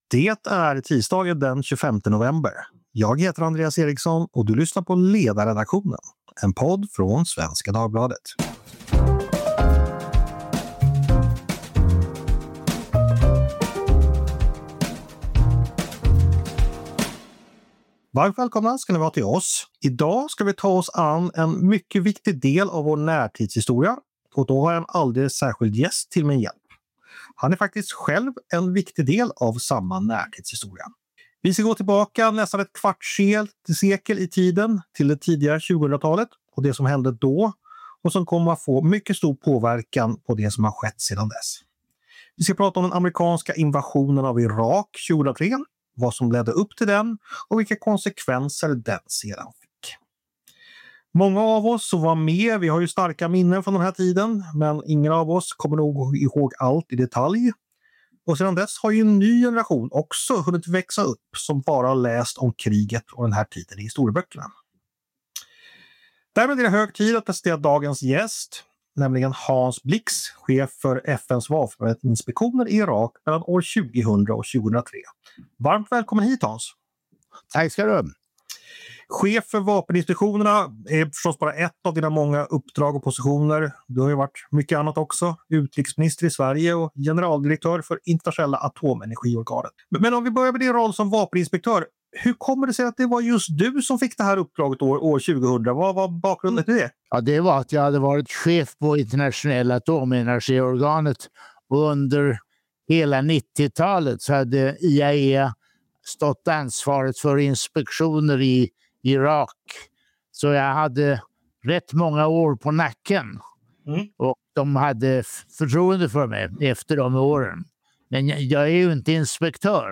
intervjuar honom om hur han idag ser på upptakten till Irakkriget och vilka lärdomar vi idag kan dra från den tiden.